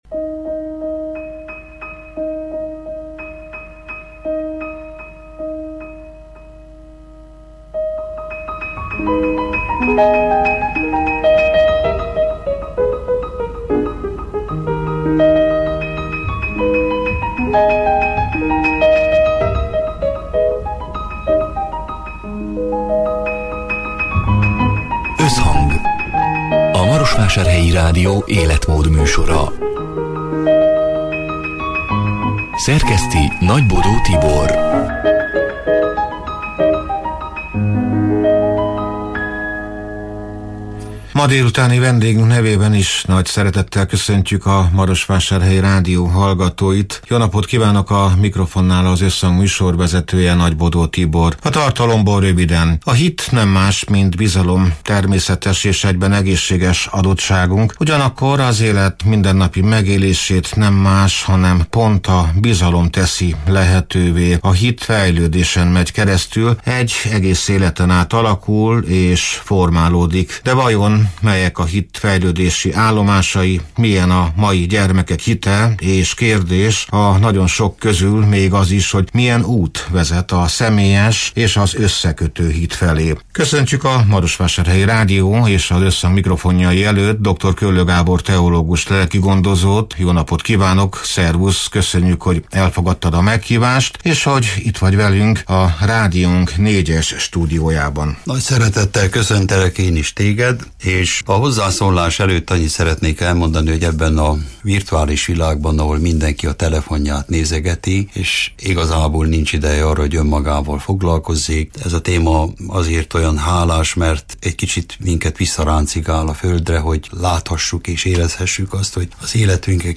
A fentiekről beszélgetünk a soron következő Összhangban, ahol a bizalom és a hit mélyebb rétegeit tárjuk fel, emberi történeteken és szakmai meglátásokon keresztül.